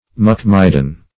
Muckmidden \Muck"mid`den\ (m[u^]k"m[i^]d`d'n), n.